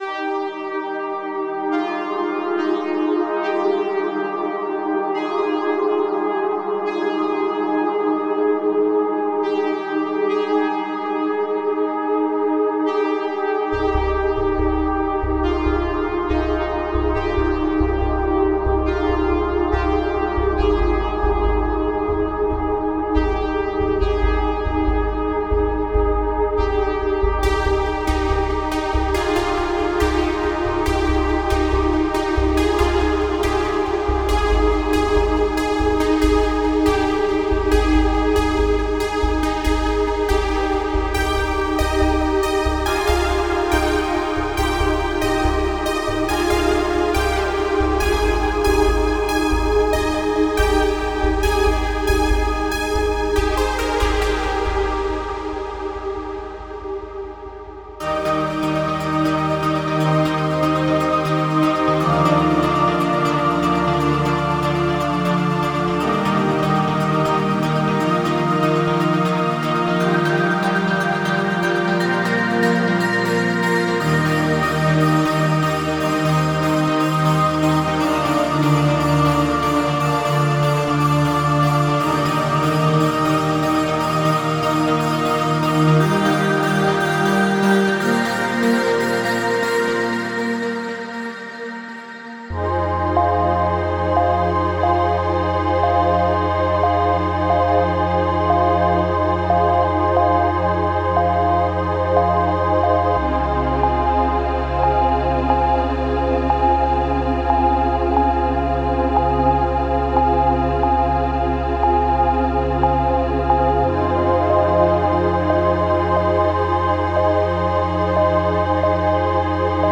Ambient Chill Out / Lounge Cinematic / FX
ethereal sounds, organic melodies and cinematic foundations
- 10 Ambient Construction Kits